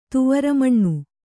♪ tuvara maṇṇu